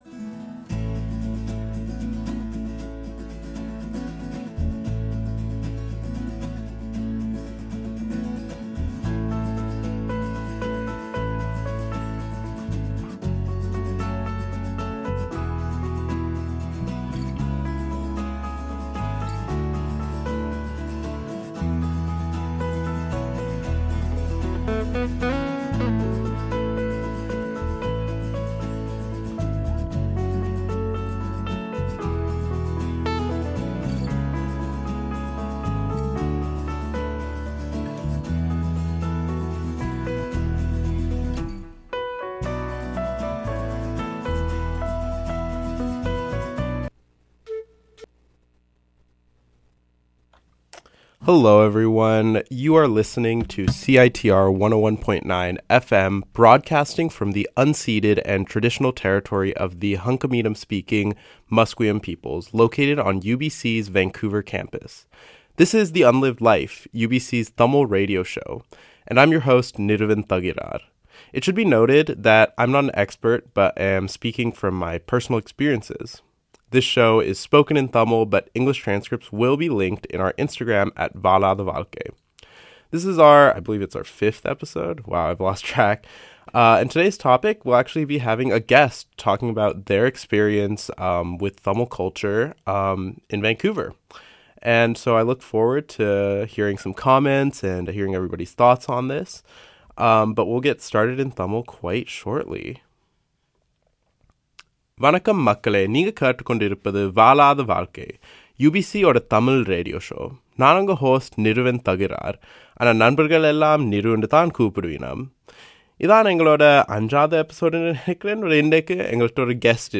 This is the first part in an ongoing series of interviews where Tamil guests discuss their experiences with Tamil culture.